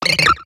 Cri de Baggiguane dans Pokémon X et Y.